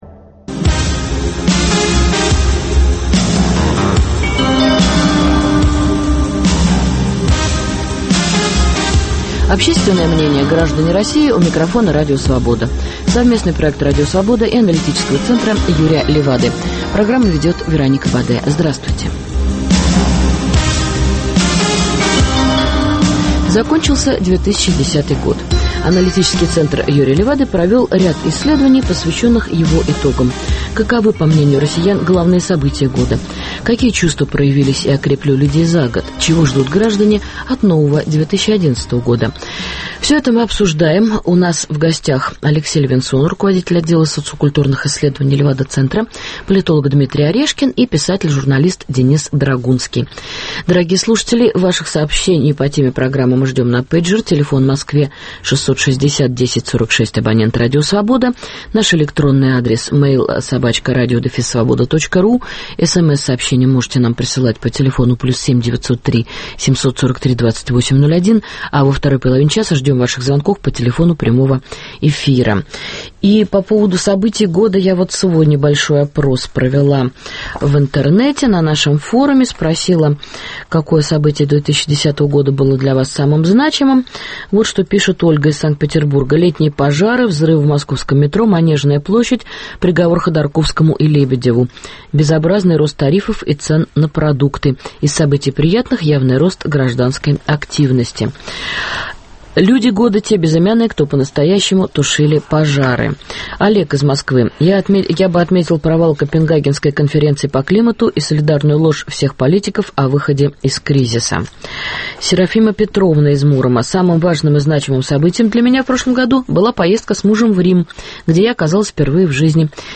Ждем звонков в прямой эфир, начиная с часа дня.